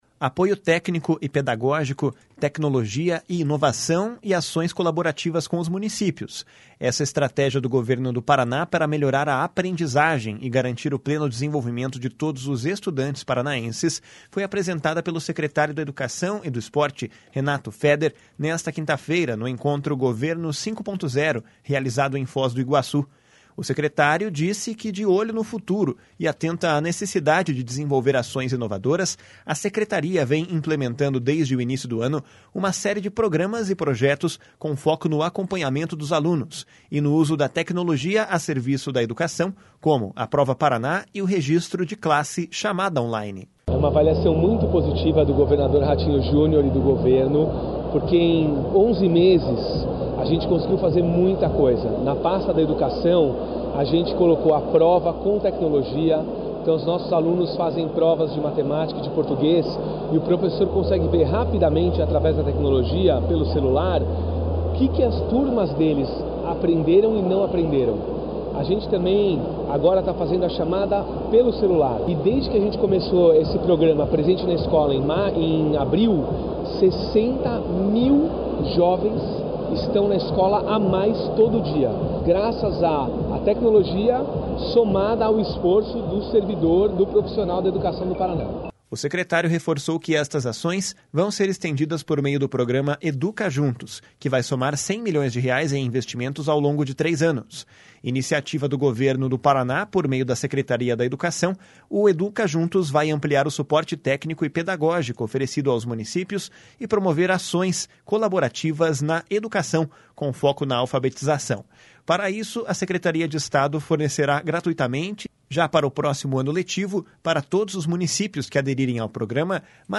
// SONORA RENATO FEDER //